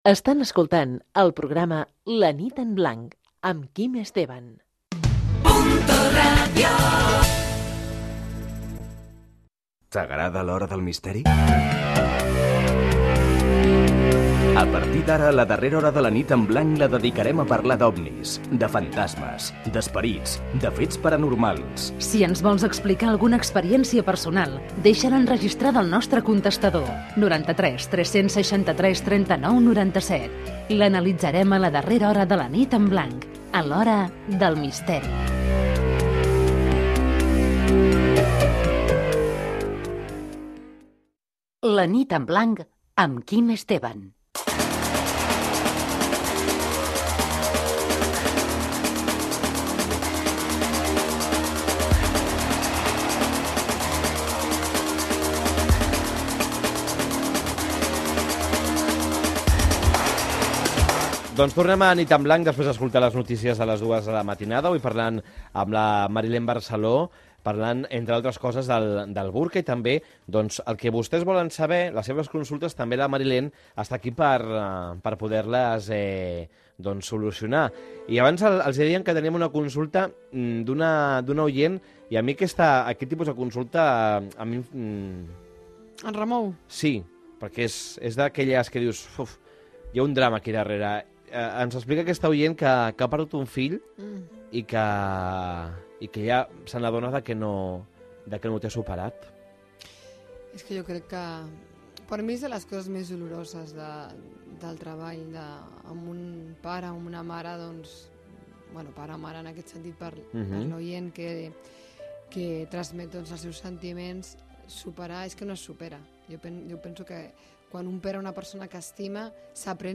Indicatius del programa i de la cadena. Telèfon de participació.
Entreteniment